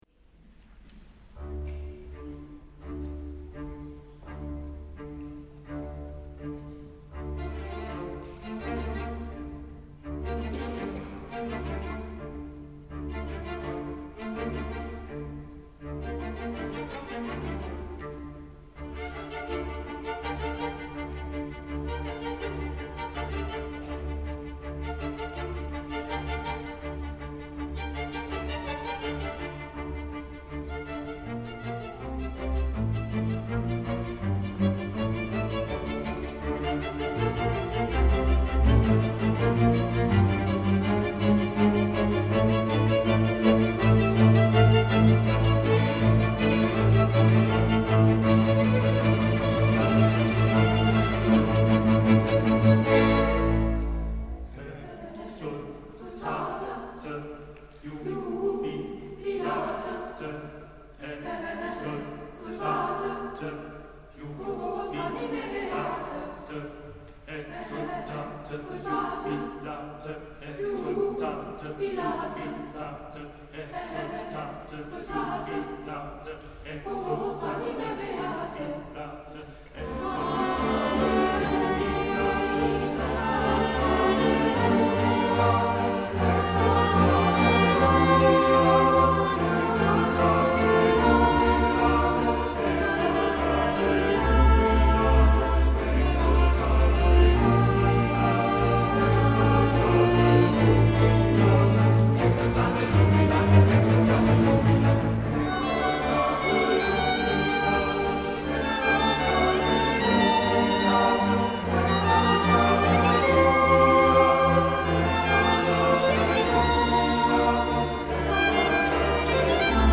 der älteste Jugendchor Berlins. Bei uns singen im Moment 15 Jugendliche und junge Erwachsene im Alter von ca. 16 bis ü30.